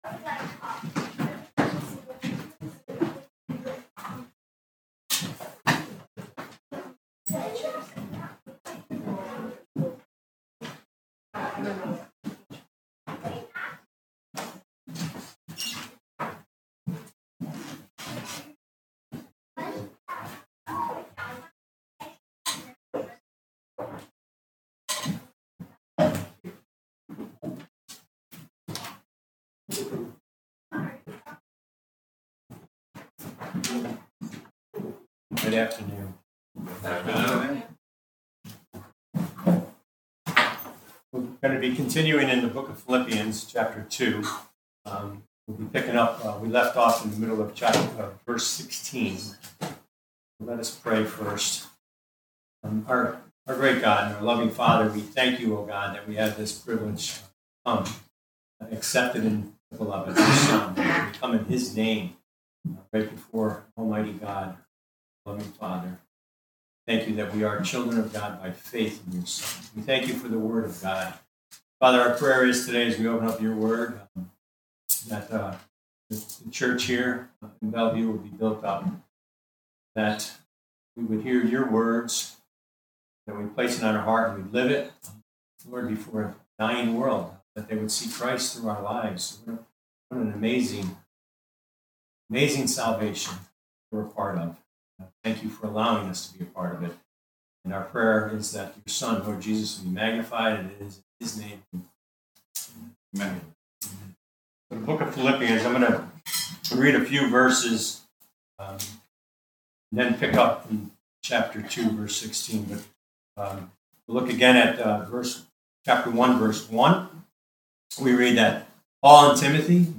Passage: Philippians 2 Service Type: Sunday Morning « 9-7-2025